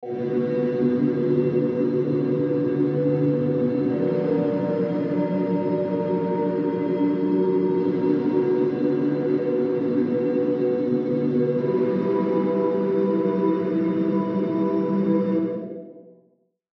Download Sci Fi Space sound effect for free.
Sci Fi Space